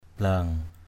/bla:ŋ/